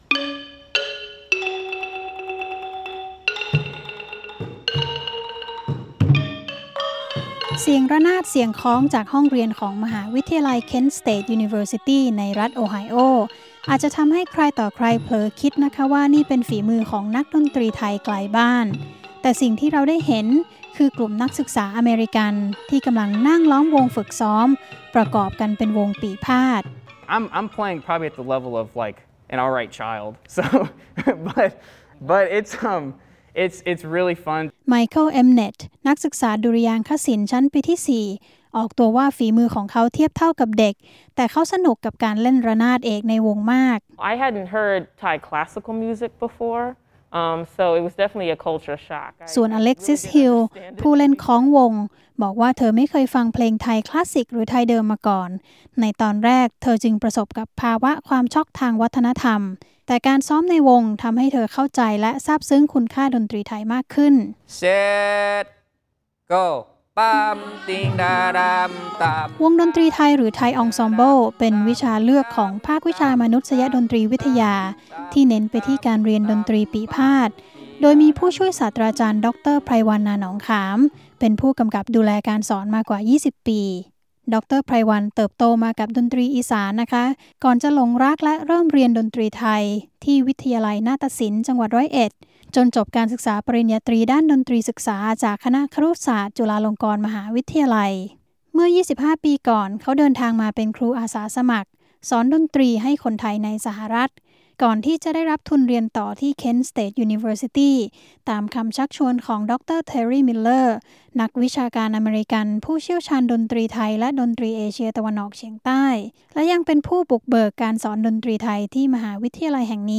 KSU Thai Ensemble การเรียนการสอนดนตรีไทย ในระดับอุดมศึกษาของสหรัฐฯ เริ่มขึ้นครั้งแรกเกือบ 60 ปีก่อน โดยมีนักวิชาการอเมริกันเป็นผู้บุกเบิก ก่อนที่จะได้รับการสานต่อโดยอาจารย์ไทย รายงานพิเศษของวีโอเอไทย จะพาไปรู้จักกับวงปี่พาทย์ หรือ Thai ensemble ที่ Kent State University รัฐโอไฮโอ...